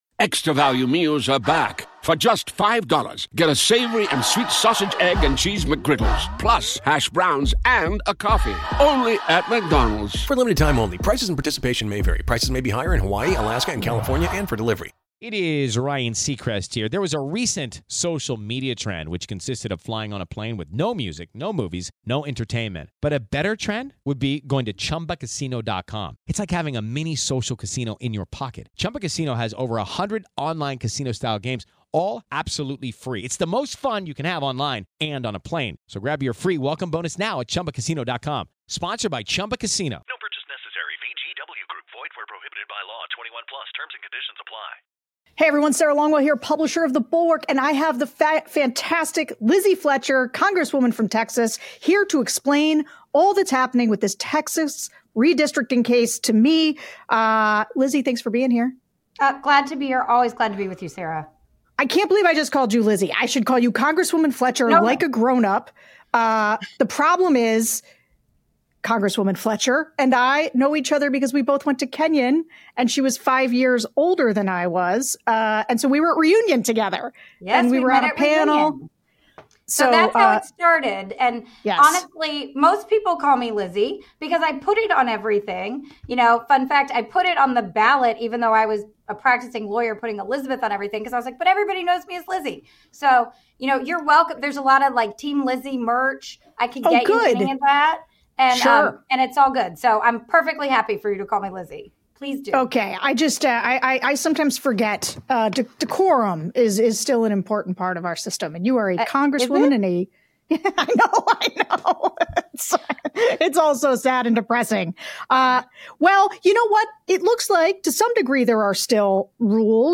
Sarah Longwell sits down with Congresswoman Lizzie Fletcher to break down the Texas redistricting ruling and why a Trump-appointed judge delivered a major surprise. They dig into what this means for 2026 and why the fight over fair maps is far from over.